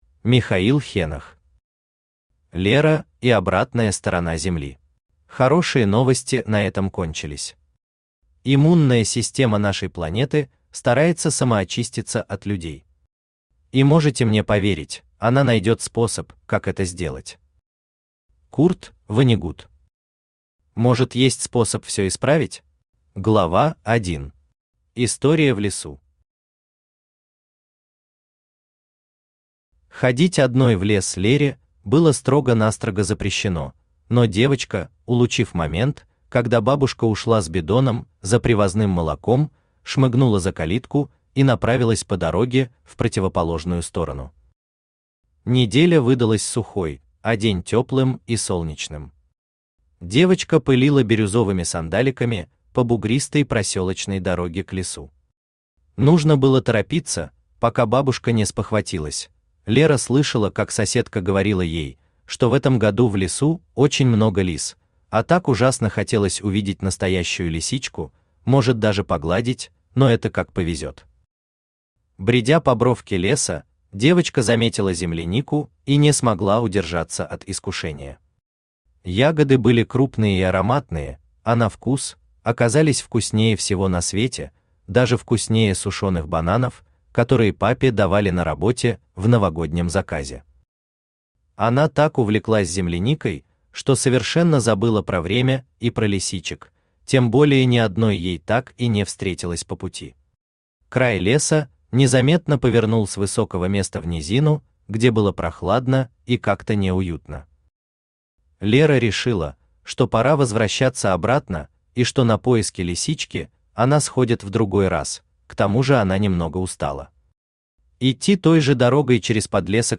Аудиокнига Лера и обратная сторона Земли | Библиотека аудиокниг
Aудиокнига Лера и обратная сторона Земли Автор Михаил Витальевич Хенох Читает аудиокнигу Авточтец ЛитРес.